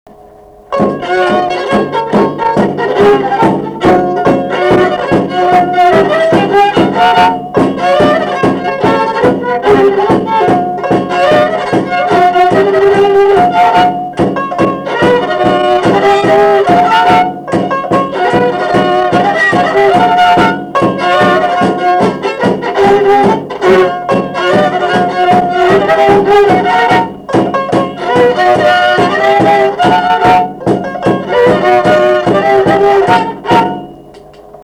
Dalykas, tema šokis
Erdvinė aprėptis Šilai (Telšiai)
Atlikimo pubūdis instrumentinis
Instrumentinė muzika